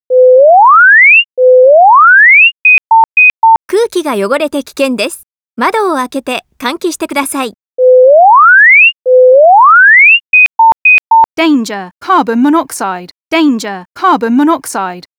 音声合成警報音※2
警報音量 70dB(A)/m以上
※2　警報音は日本語、日本語⇔英語の交互音声、日本⇔中国語の交互音声、ブザー音の切替可能。
CO警報
co_warning_voice_jp_en.wav